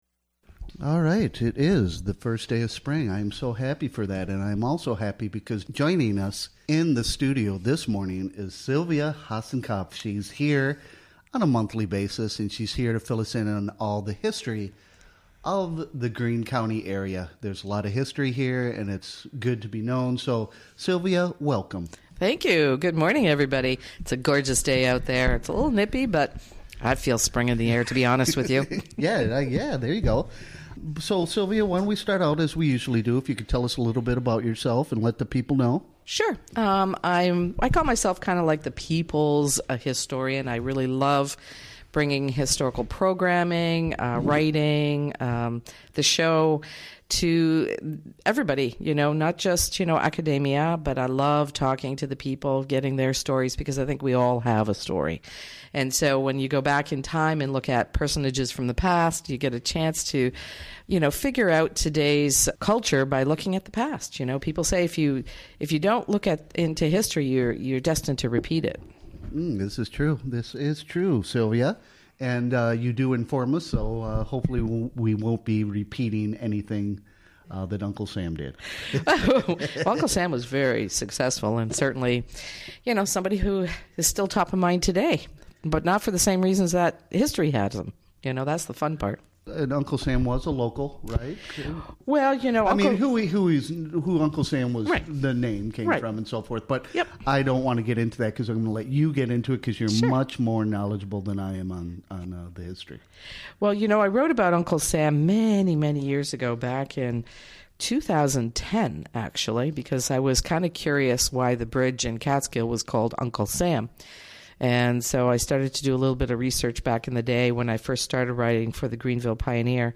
This regular monthly feature was recorded live during the WGXC Morning Show of Tue., March 20, 2018.